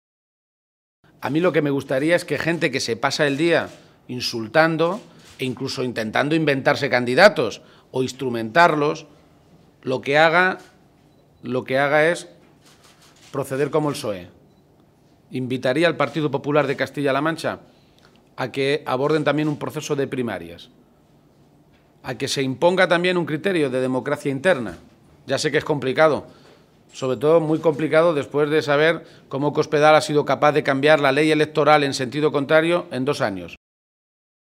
El líder de los socialistas castellano-manchegos ha realizado estas declaraciones en un desayuno informativo en Ciudad Real, donde ha estado acompañado por el secretario provincial del PSOE en esta provincia, José Manuel Caballero, por la secretaria de Organización, Blanca Fernández, y por la portavoz regional, Cristina Maestre.